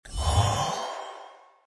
magic potion.mp3